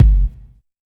20 BOOM KK-L.wav